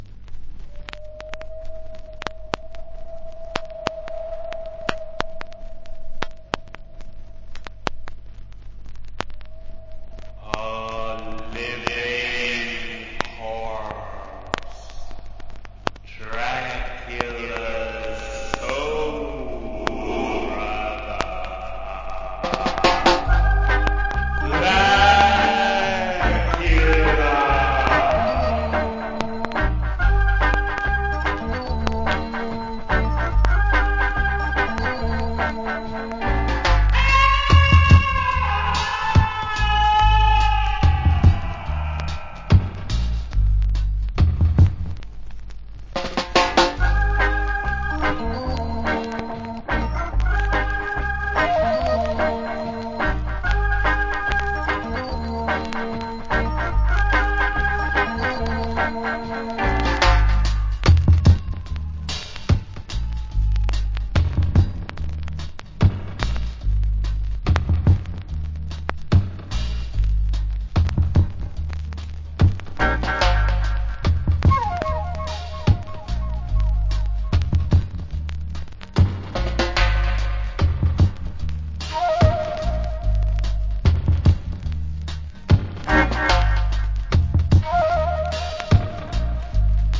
Heavy Reggae Inst.